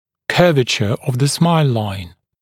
[‘kɜːvəʧə əv ðə smaɪl laɪn][‘кё:вэчэ ов зэ смайл лайн]кривизна линии улыбки (т.е. ее соотношение линии верхних зубов с нижней губой)